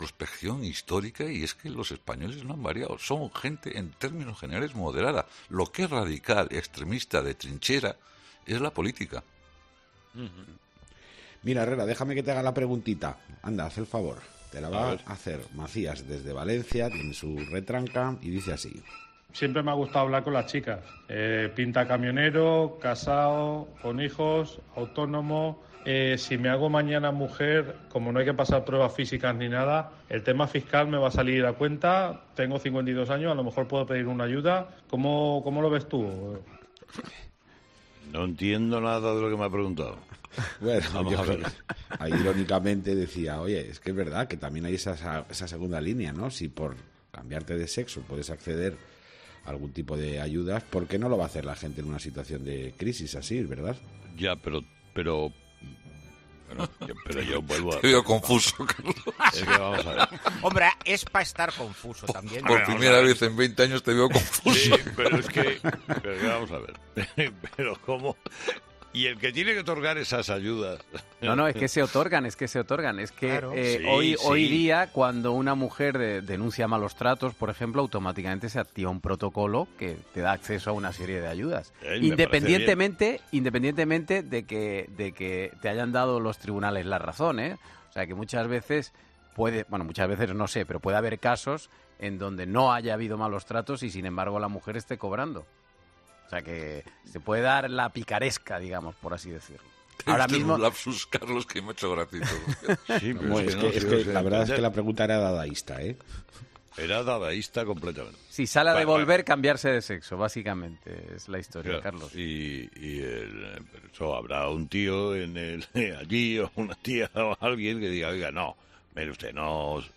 "Por primera vez en 20 años": la pregunta de un oyente que ha dejado mudo a Carlos Herrera
Carlos Herrera respondió hace unas semanas a las dudas de los oyentes en 'Herrera en COPE'
"Por primera vez en 20 años, tengo una confusión", respondía Herrera entre risas.